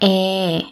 Very soft pronouncing of “r” at the end.
aeae(r)